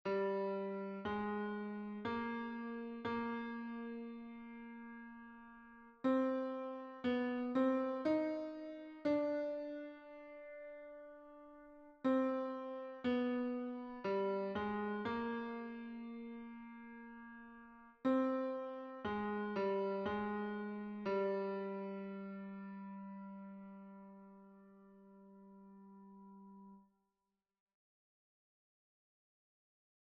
Version piano
Tenor Mp 3